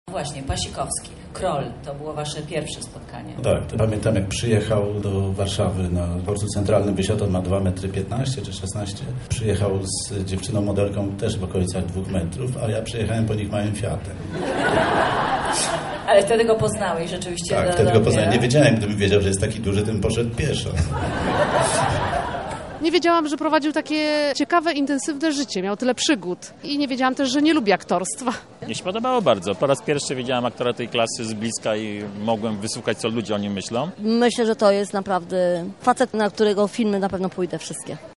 W sali wypełnionej po brzegi ludźmi rozmawiała z nim dyrektor artystyczna festiwalu Grażyna Torbicka.